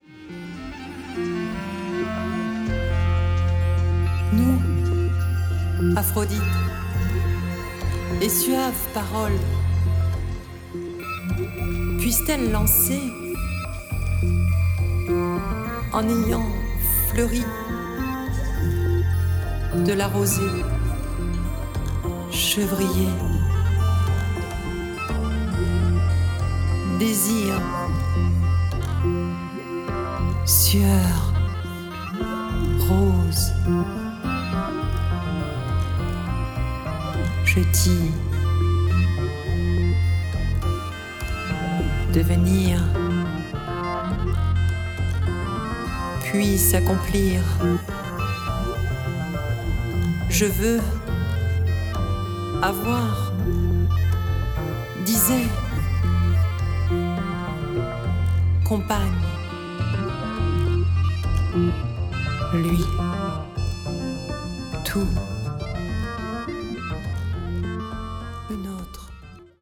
Diseuse de Texte
Percussions (Grosse caisse et cymbales), Basse, Qanun, Sax soprano, Clarinette, Karamuza, deux Synthétiseurs, dont un pour une séquence itérative, et, pour finir, diverses ambiances sonores dominées par la mer, nous sommes à Mytilène sur l’île de Lesbos, nom de Zeus, d’Aphrodite et de tout l’Olympe!
Et puis je ne pouvais, décemment, laisser la merveilleuse intonation de la diseuse dans le Kolpos Yieras!